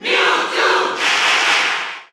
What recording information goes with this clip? Mewtwo_Cheer_German_&_Italian_SSB4_SSBU.ogg